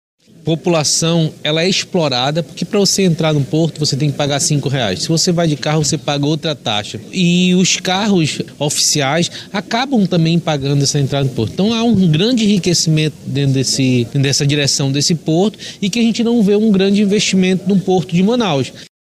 Sonora-Carlinhos-Bessa-–-deputado-estadual.mp3